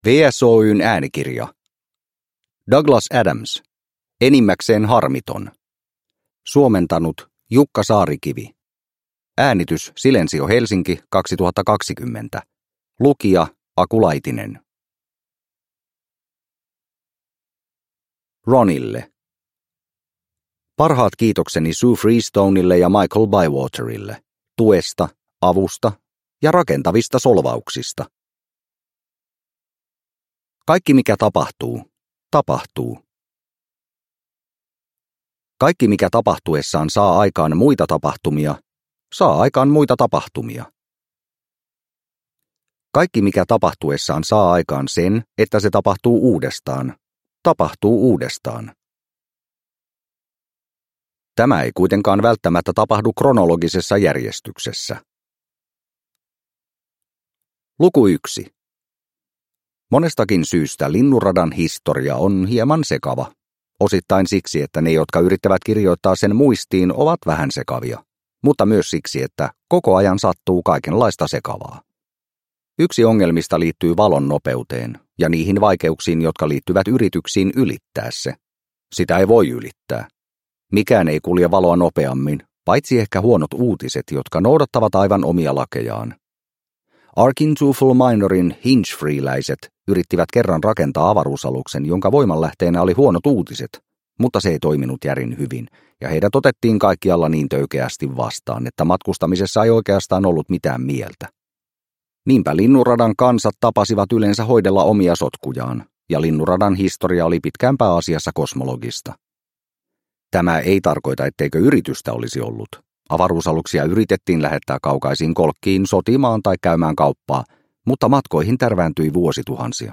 Enimmäkseen harmiton – Ljudbok – Laddas ner